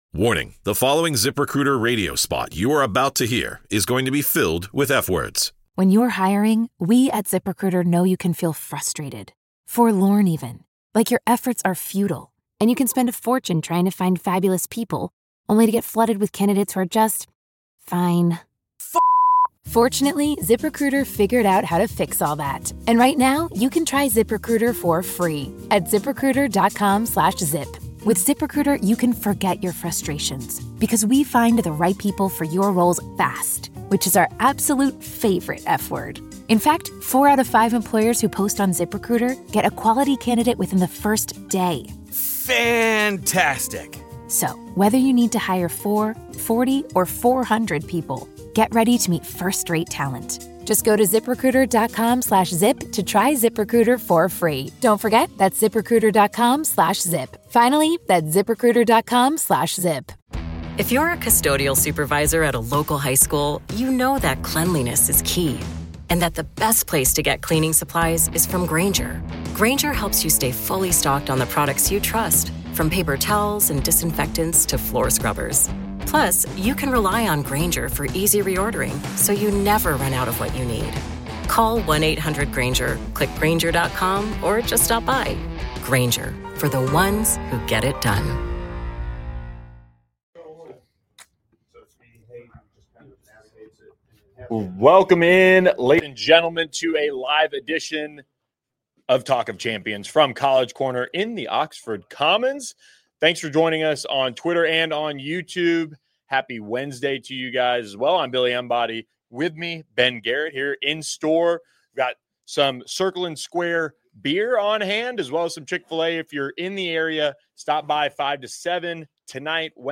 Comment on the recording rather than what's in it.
Ole Miss football fall camp report from College Corner